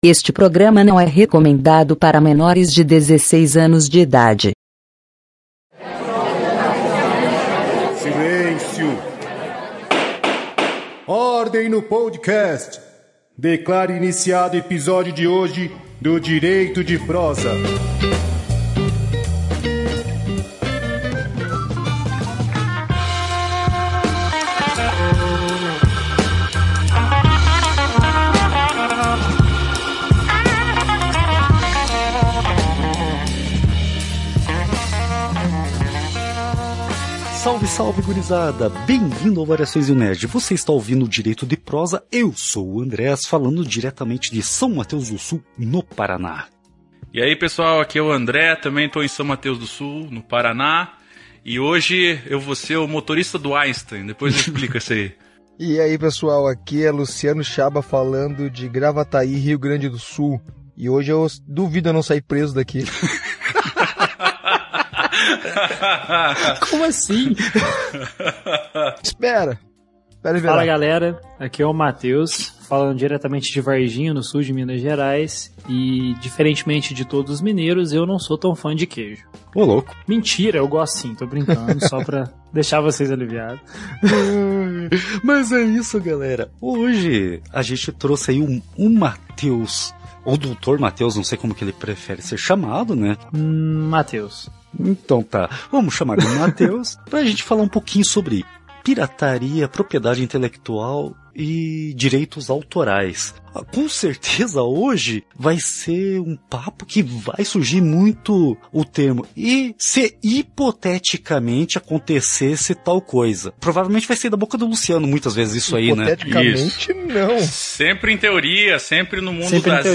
Nesse episódio batemos um papo com um advogado especialista no camo de direitos autorais, propriedade intelectual e pirataria.